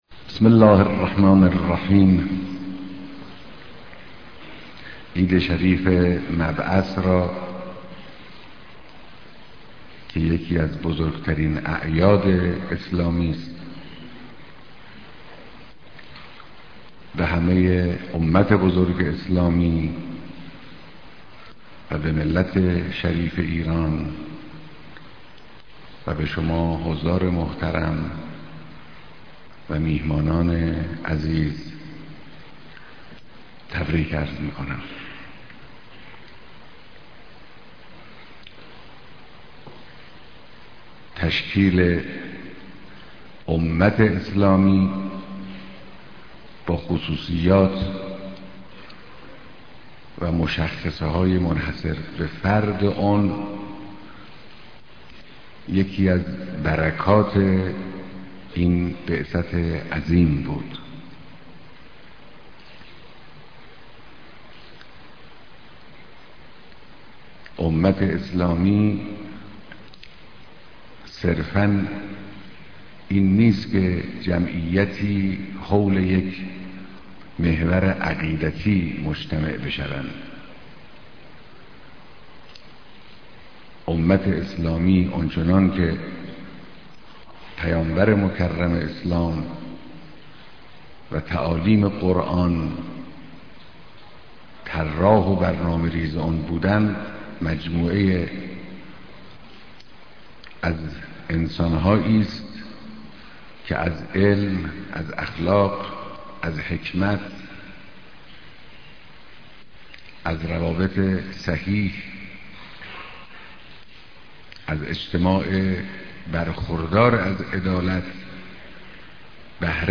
مراسم اختتاميه بيست و يكمين دوره مسابقات بينالمللى قرآن كريم
بيانات در ديدار قاريان شركت كننده در بيست و يكمين دوره مسابقات بينالمللى قرآن كريم